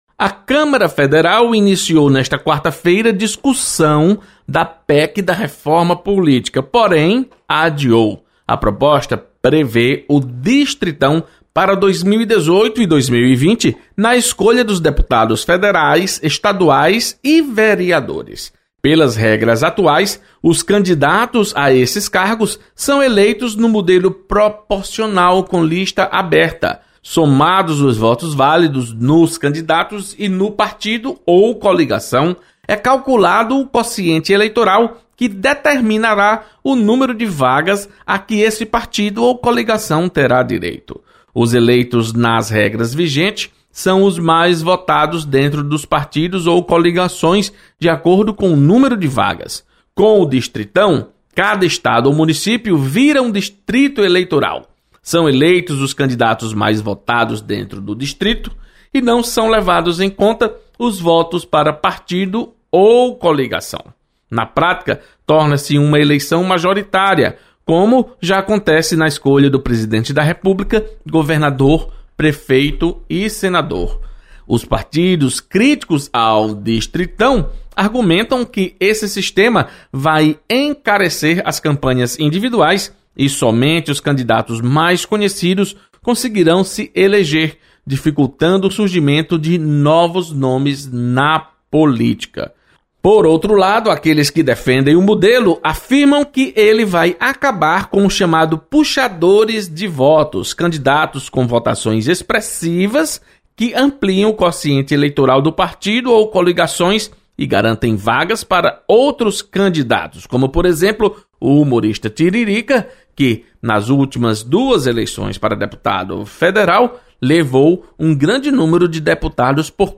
Deputado Renato Roseno critica proposta de reforma política. Repórter